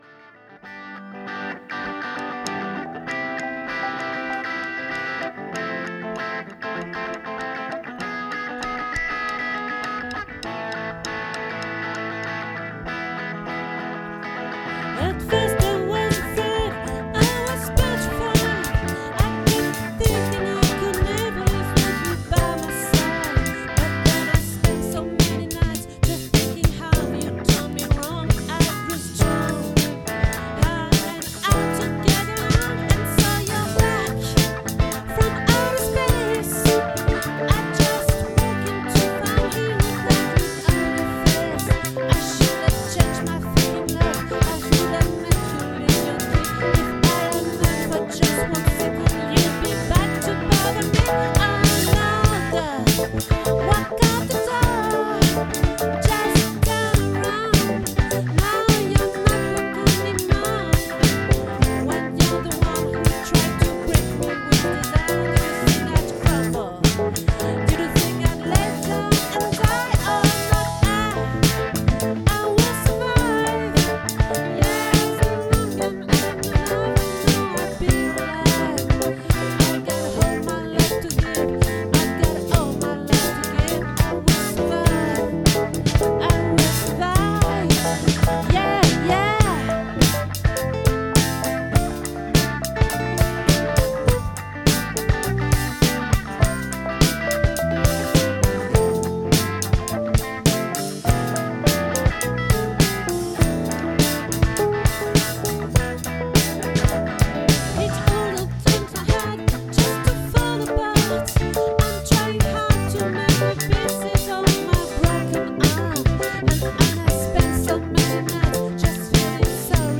🏠 Accueil Repetitions Records_2025_02_17